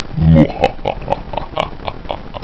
dead.wav